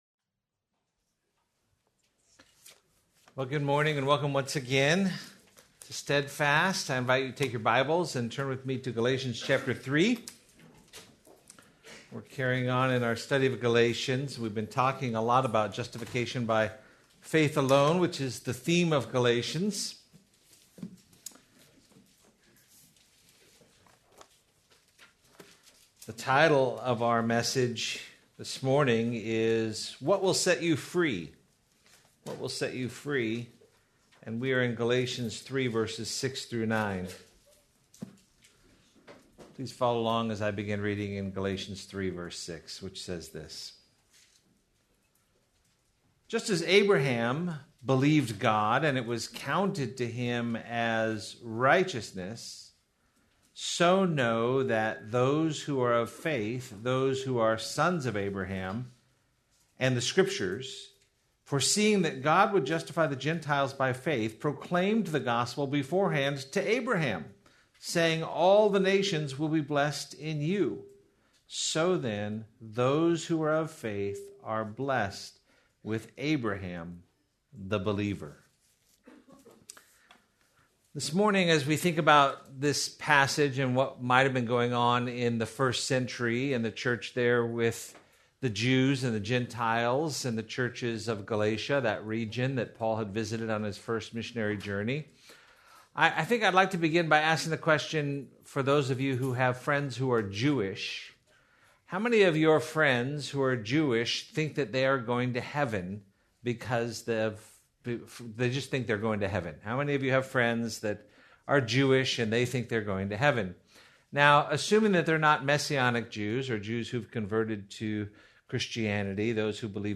Please note, due to technical difficulties, this recording skips brief portins of audio.